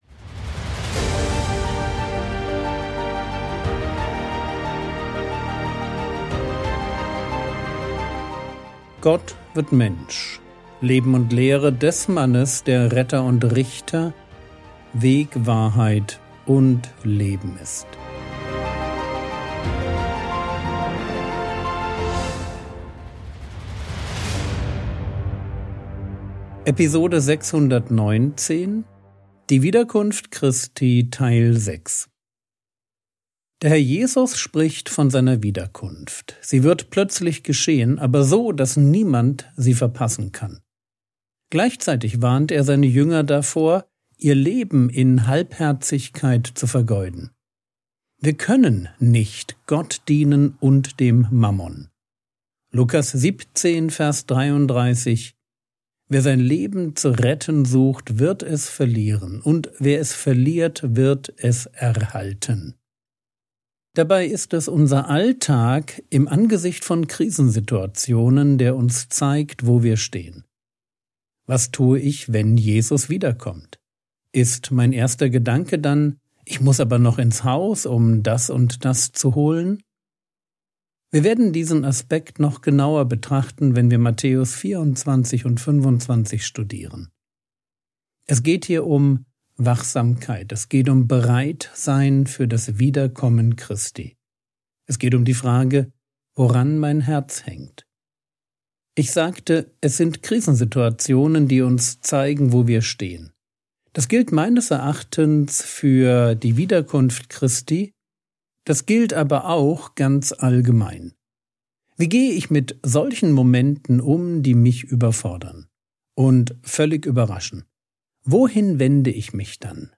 Episode 619 | Jesu Leben und Lehre ~ Frogwords Mini-Predigt Podcast